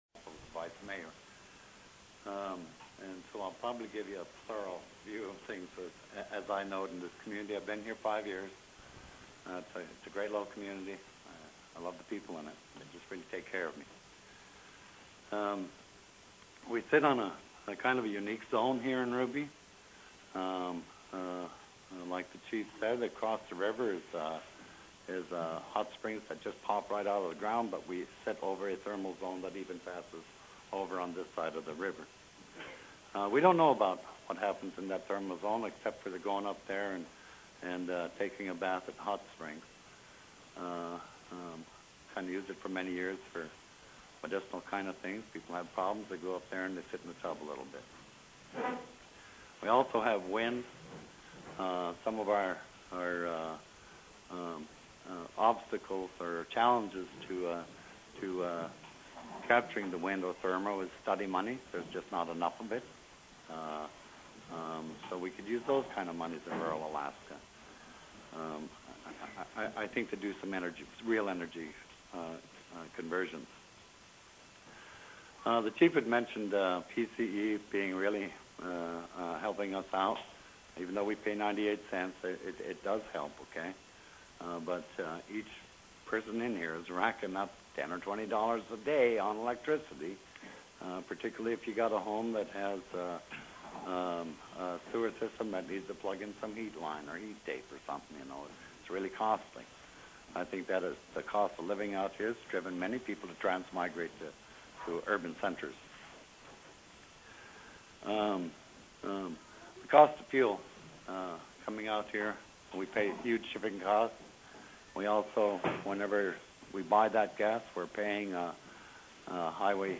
Location: Ruby Community Center
Public Hearing on Statewide Energy Plan TELECONFERENCED
It was scheduled to start at approximately 3:30 p.m. ^ Public Hearing on Statewide Energy Plan Public Hearing on Statewide Energy Plan Due to technical difficulties, the first few minutes of the meeting were not recorded.